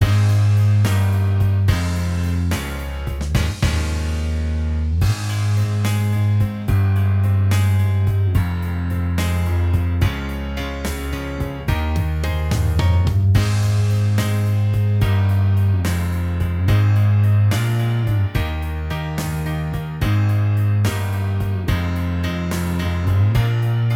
Minus Lead Guitar Rock 4:58 Buy £1.50